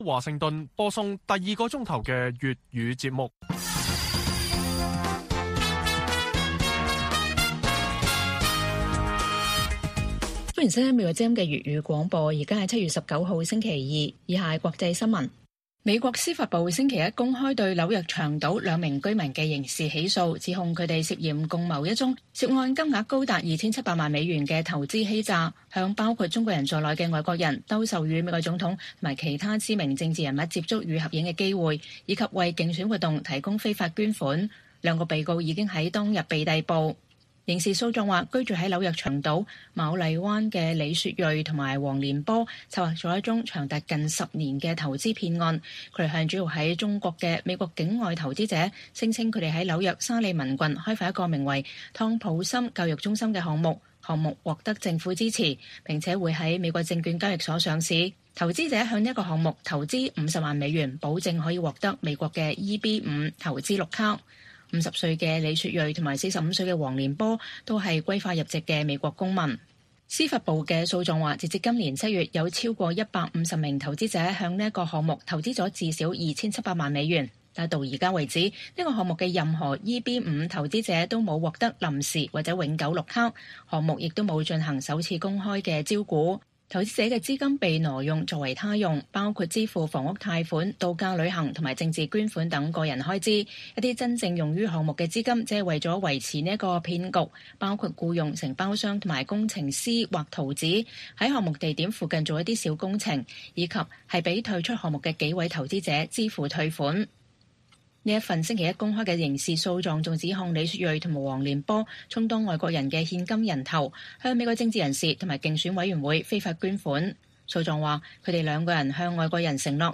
粵語新聞 晚上10-11點: 美參院預計本週表決“濃縮版”投資法案為半導體提供補貼